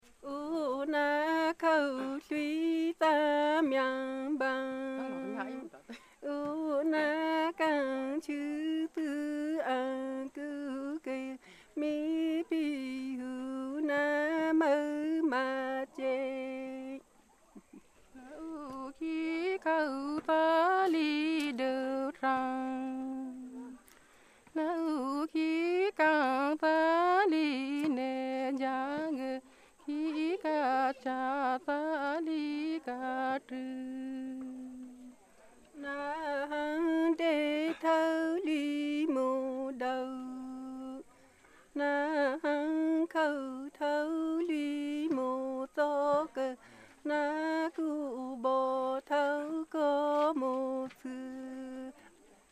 courting song the same two women alternate 821KB